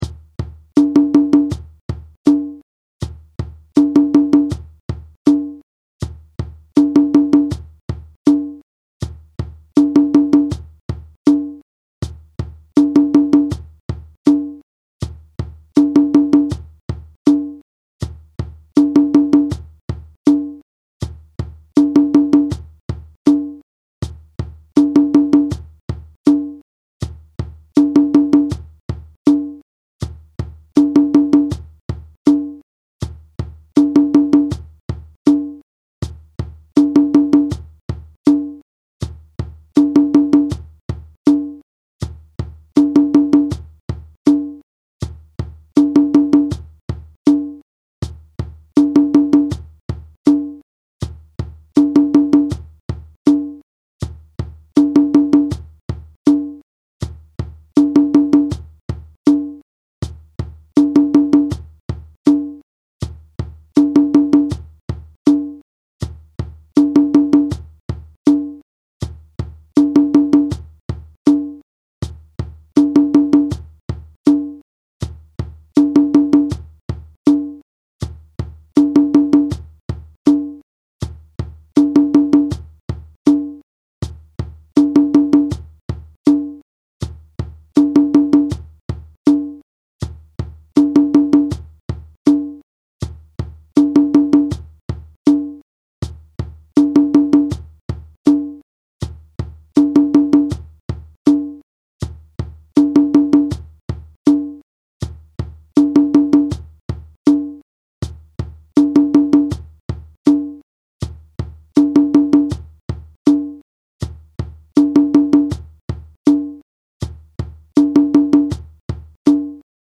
• Play-Along Audio (available in 4 speeds)
audio (with shekeré)